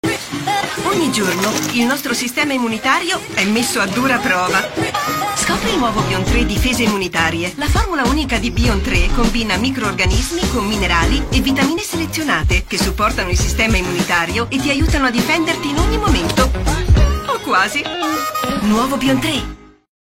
Istituzionale/Tecnico